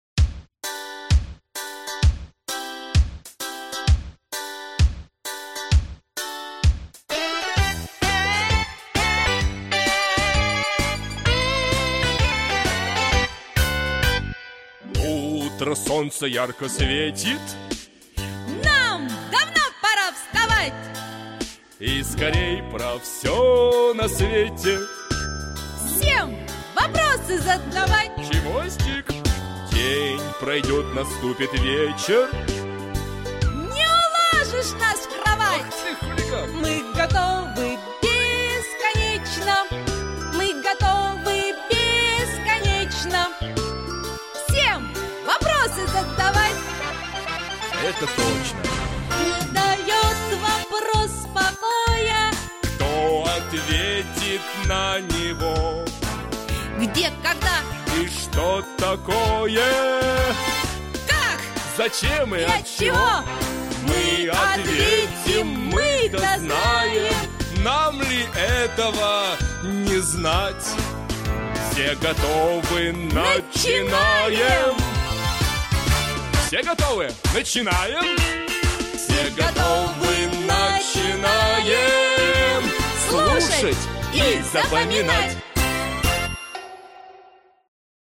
Аудиокнига Космодромы России | Библиотека аудиокниг
Прослушать и бесплатно скачать фрагмент аудиокниги